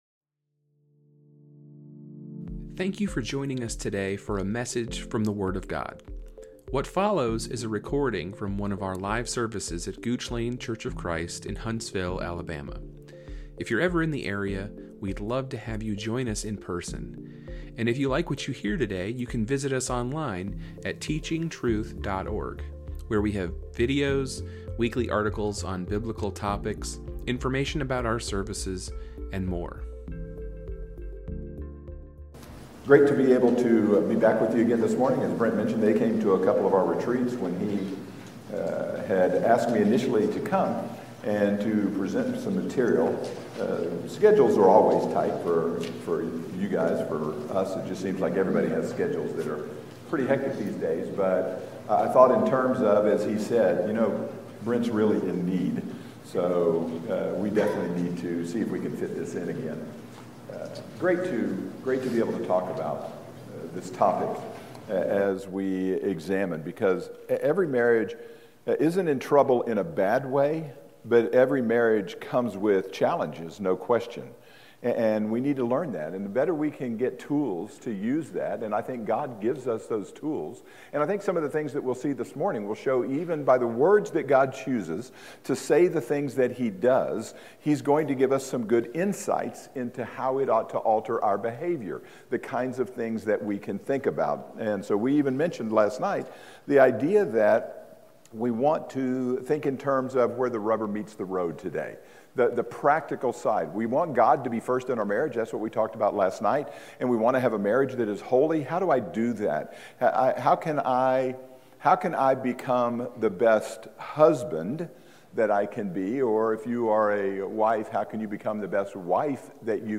Lesson two of a seven part marriage and family series